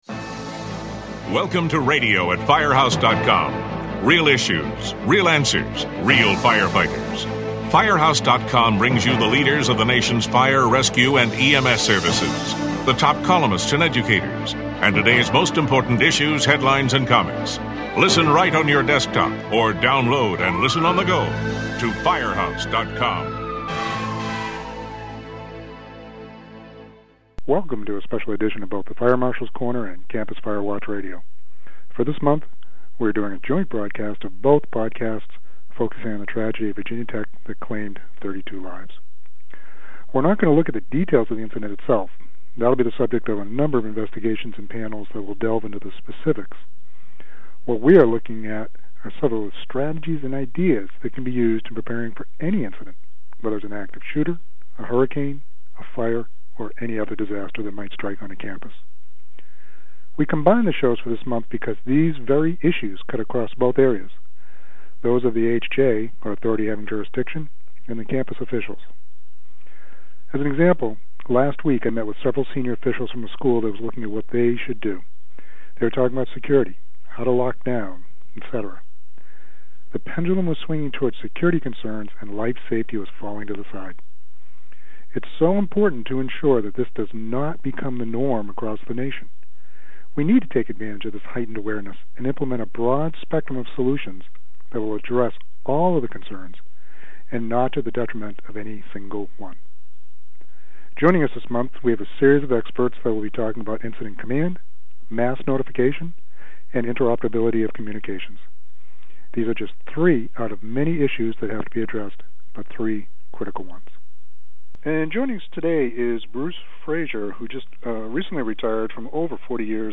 We have brought together a series of speakers to look at three (of many) concerns regarding safety on campuses. Tempe, AZ, Fire Chief Cliff Jones discusses the importance of incident management.